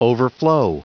Prononciation du mot overflow en anglais (fichier audio)
Prononciation du mot : overflow